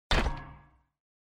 ui_interface_25.wav